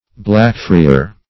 Blackfriar \Black"fri`ar\, Black friar \Black"
fri`ar\(bl[a^]k"fr[imac]`[~e]r). (Eccl.)